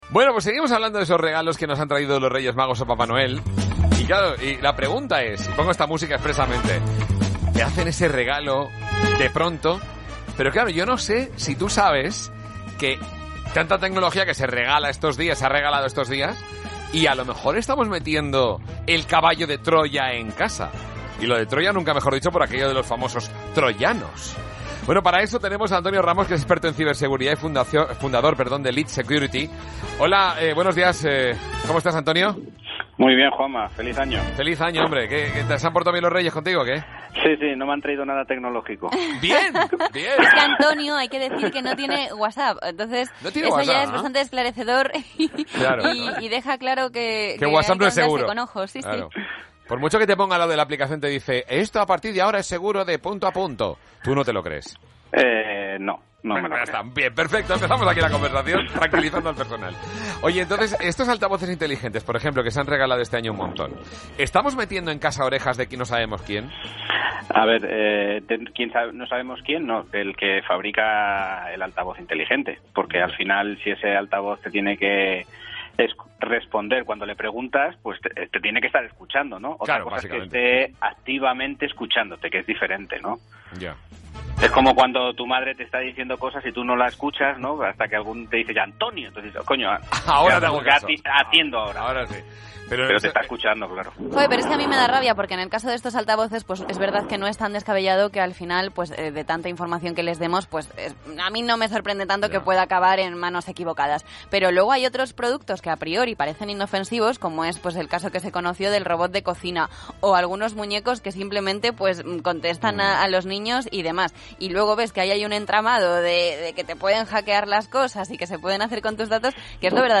Entrevista a nuestro CEO en Melodía FM